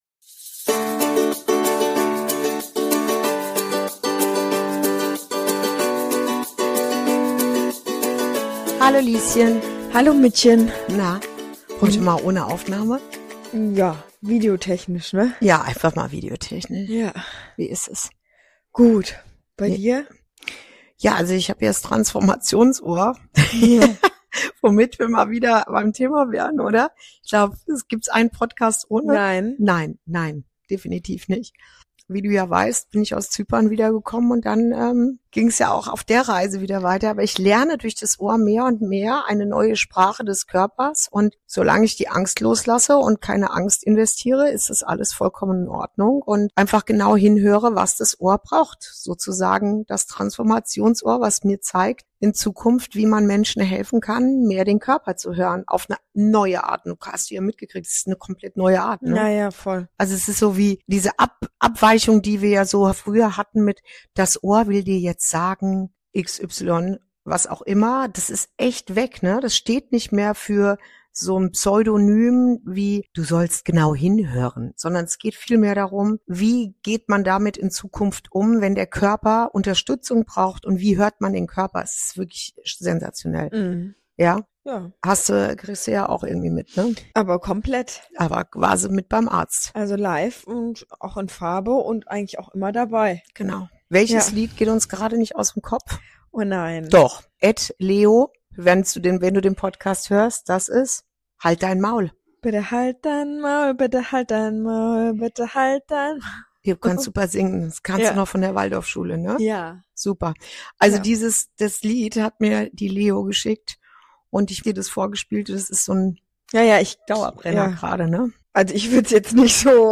Folge 15: Abschied, Alltag & ein Feuerwehrherz auf drei Rädern ~ Inside Out - Ein Gespräch zwischen Mutter und Tochter Podcast